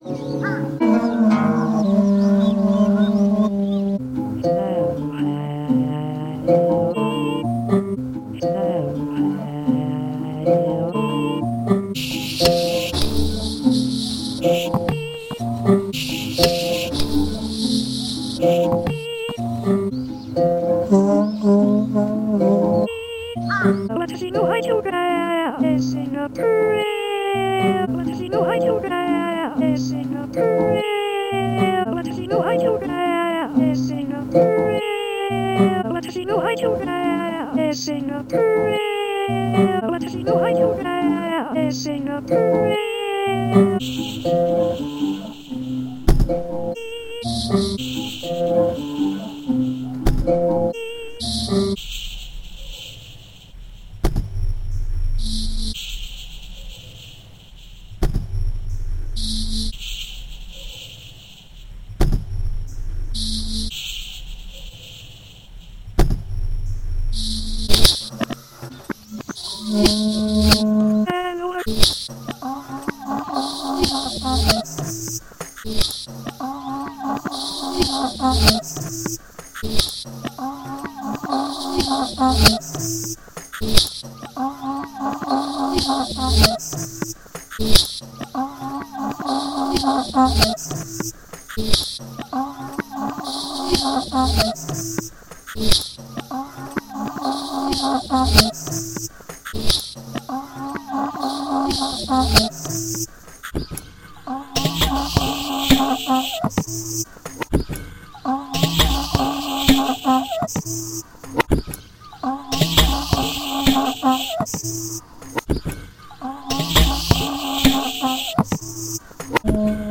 yellow teapot y detuned kora